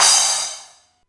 Index of /musicradar/essential-drumkit-samples/DX:DMX Kit
DX Cymbal 03.wav